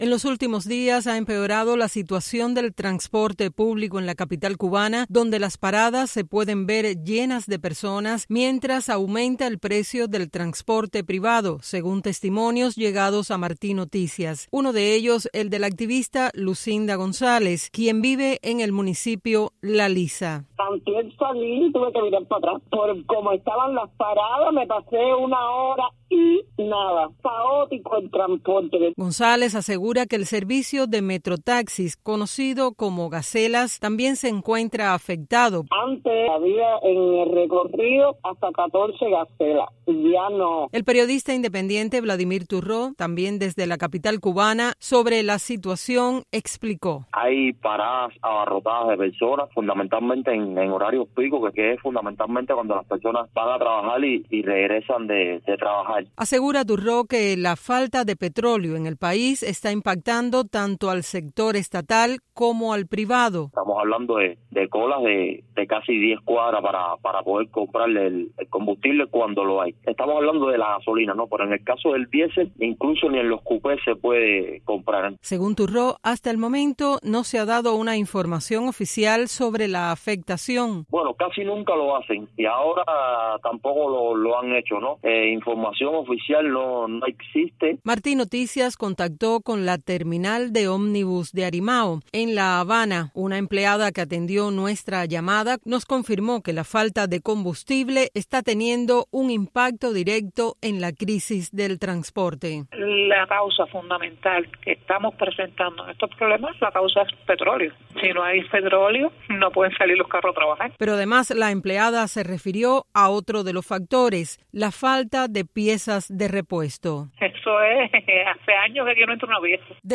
Precisamente desde la capital cubana, varios entrevistados por Martí Noticias constatan un empeoramiento de la situación del transporte público, que se ve reflejado en las paradas abarrotadas de personas y el aumento del precio del transporte privado.